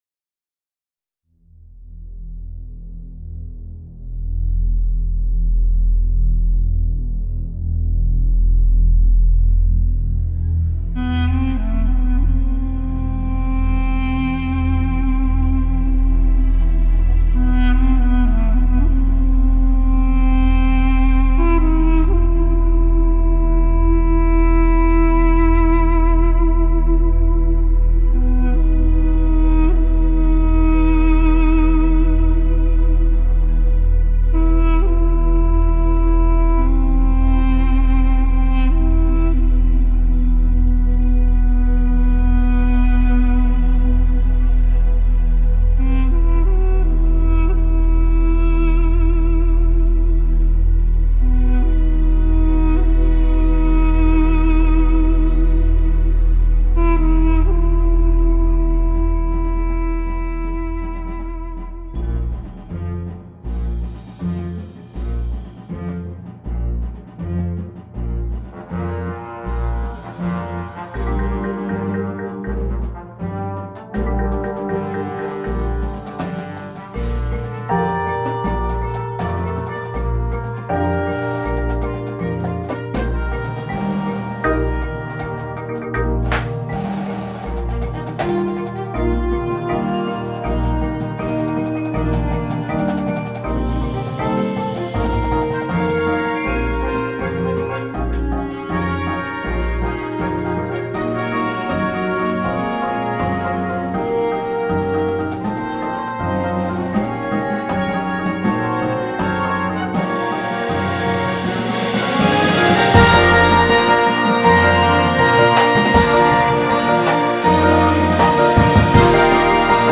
2026 Marching Band Show Audio